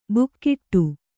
♪ bukkiṭṭu